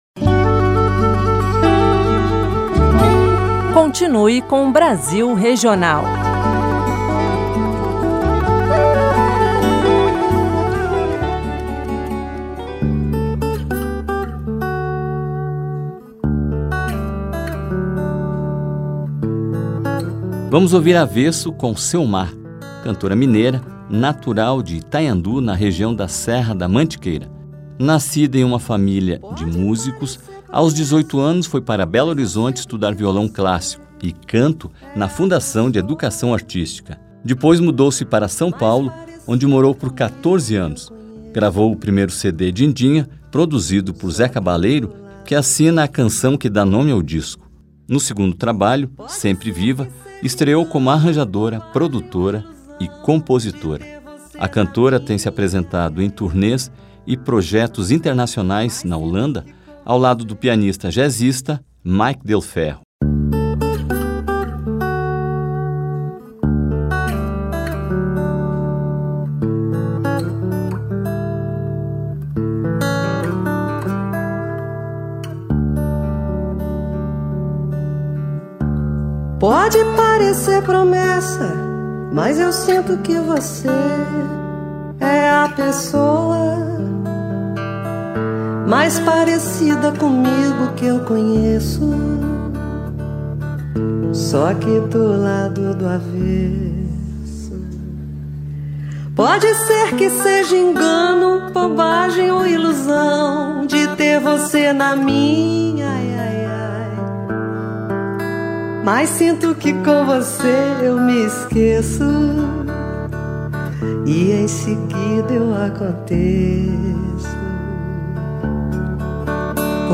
segue a trilha da brasilidade latina nas canções